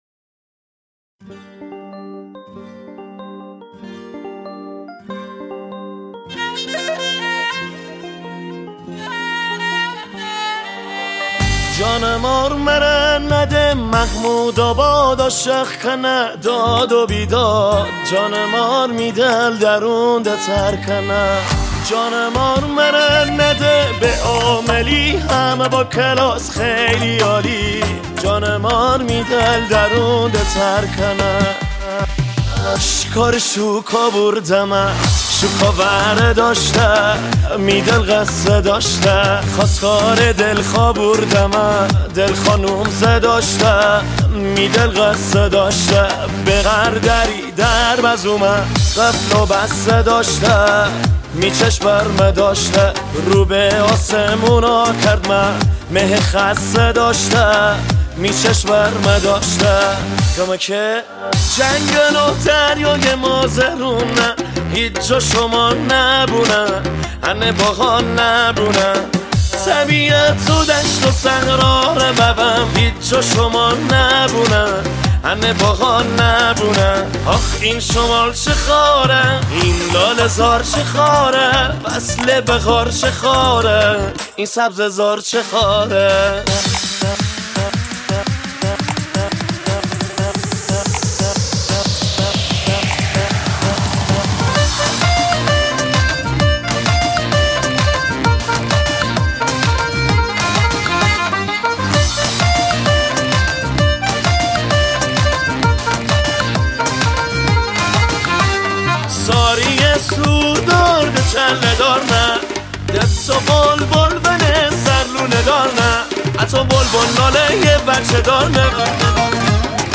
دانلود آهنگ مازندرانی جدید زیبا
گیتار
آهنگ شاد